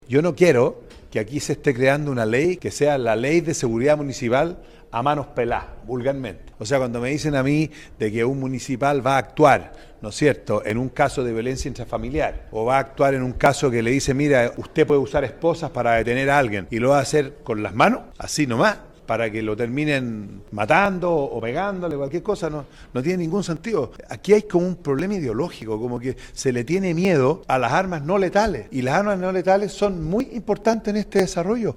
El senador Manuel José Ossandón (RN), presidente de la Comisión de Gobierno, cuestionó la falta de claridad sobre el uso de armas no letales.